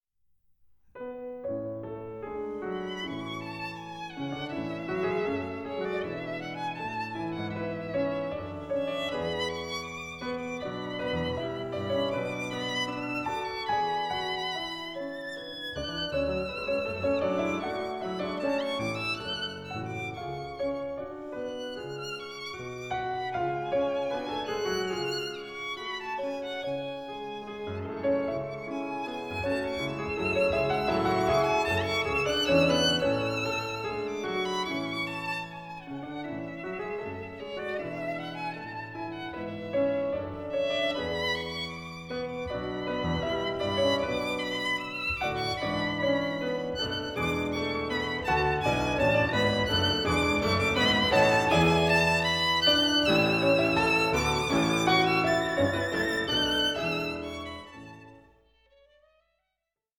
(48/24, 88/24, 96/24) Stereo  14,99 Select
rich and wide sound